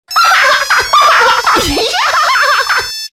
Klingelton
Zugegeben: für diesen Klingelton braucht man wirklich starke Nerven.